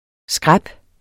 Udtale [ ˈsgʁab ]